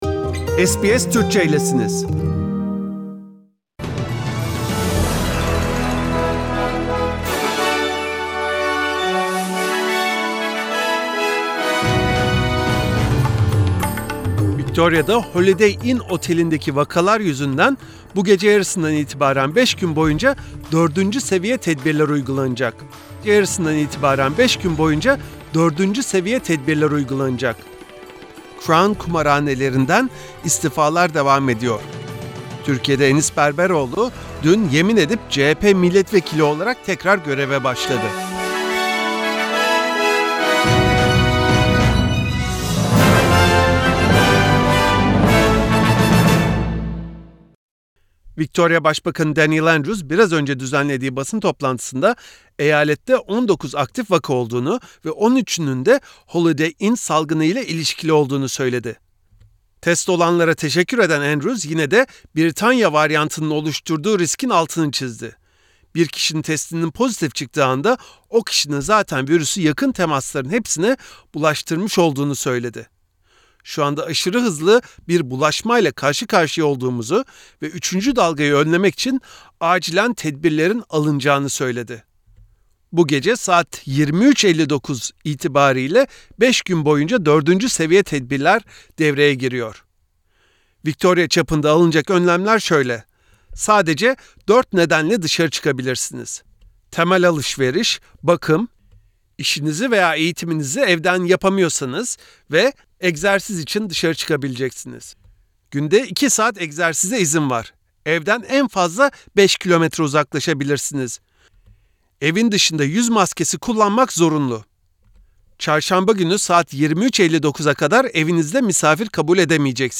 SBS Türkçe Haberler 12 Şubat